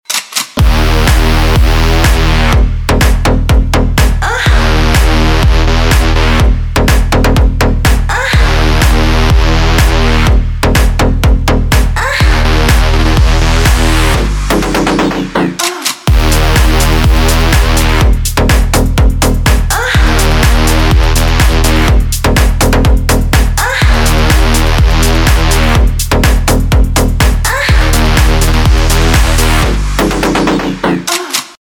• Категория: Клубные рингтоны